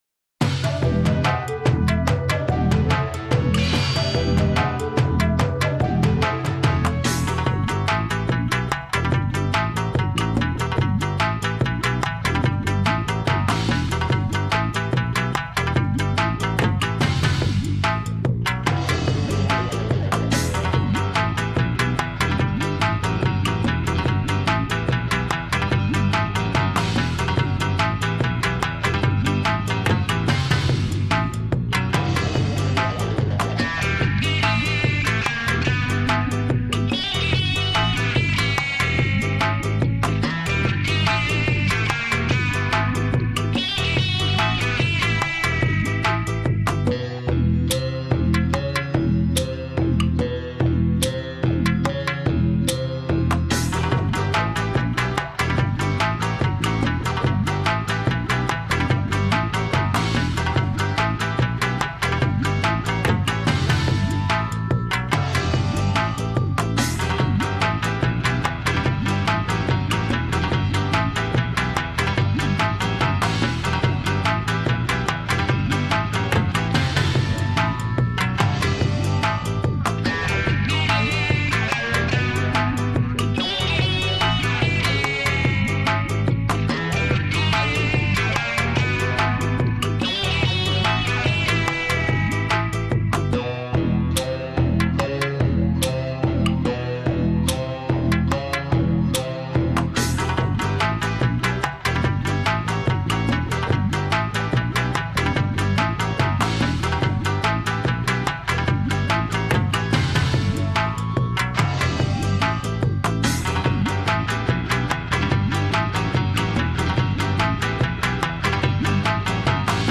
Japanese 007- A modern theme using traditional instruments.